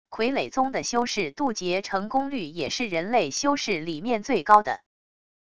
傀儡宗的修士渡劫成功率也是人类修士里面最高的wav音频生成系统WAV Audio Player